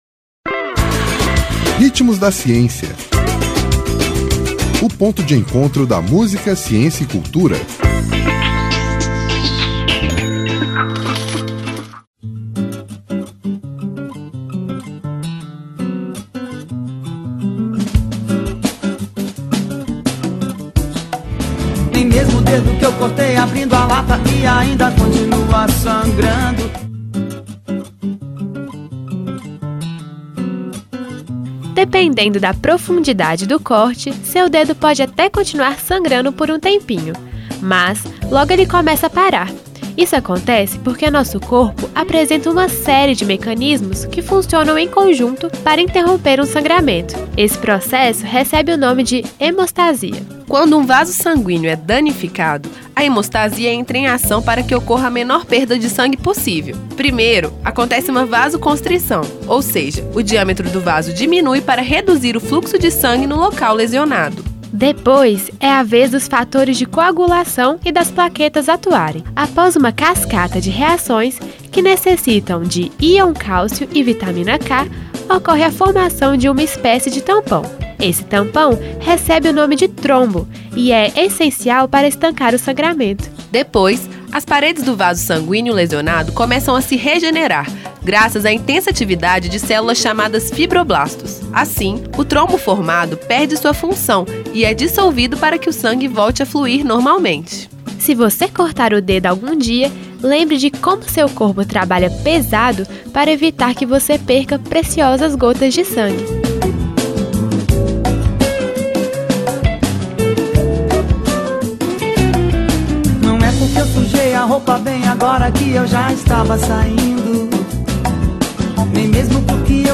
Nome da música: Meu mundo ficaria completo
Intérprete: Cássia Eller